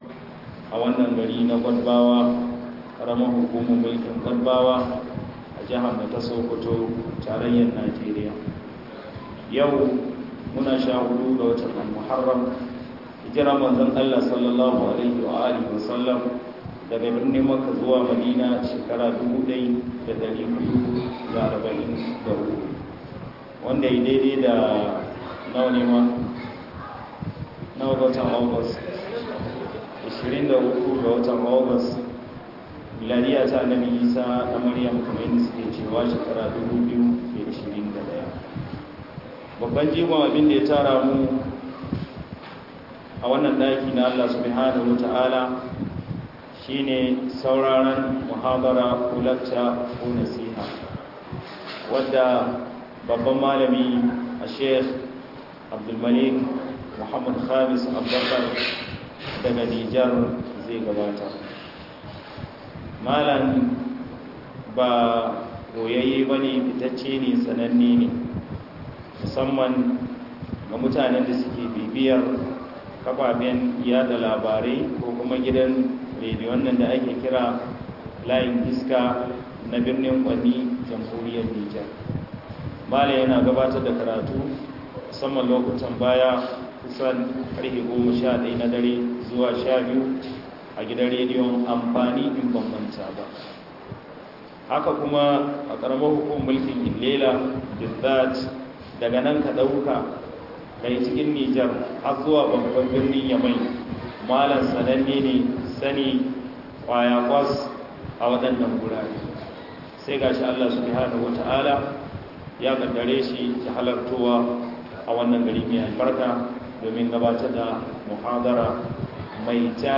Kalubalen da kasashen musulmai ke fuskanta - MUHADARA